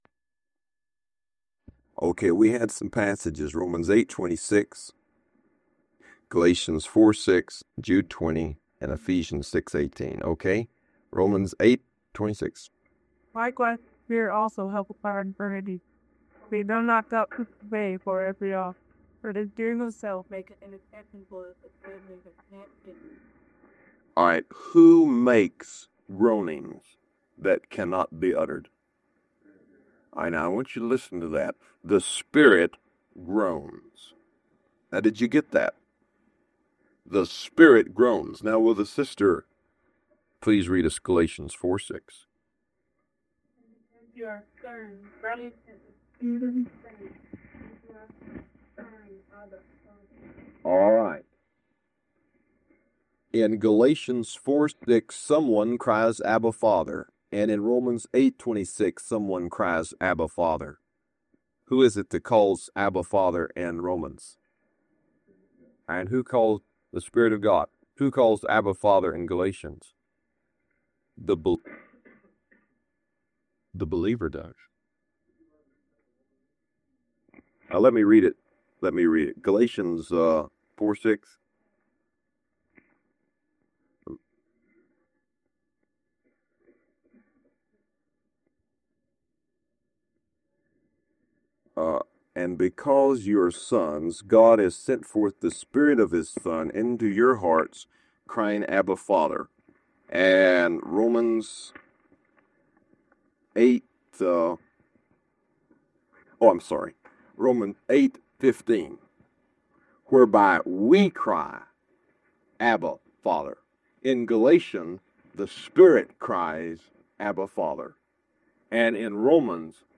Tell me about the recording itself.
Messages to the Church in Isla Vista, CA